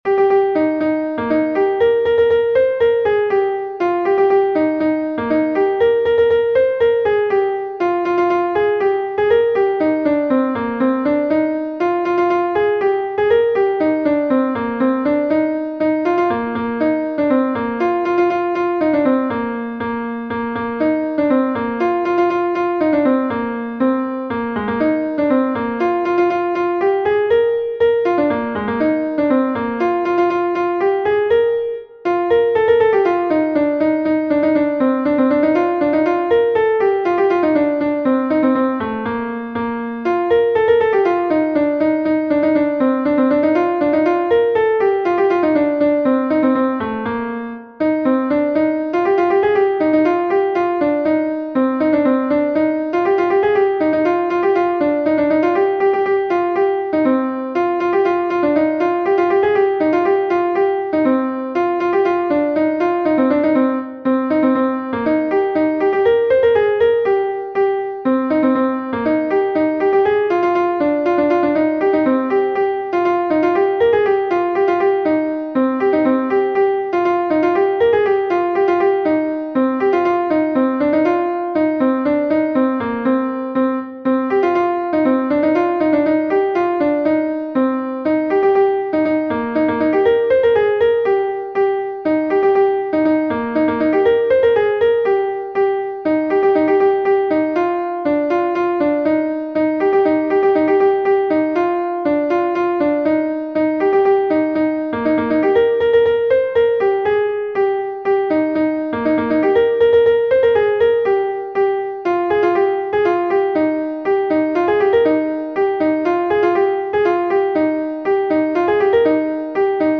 Gavotenn Er Gemene Bro-Bourled II est un Gavotte de Bretagne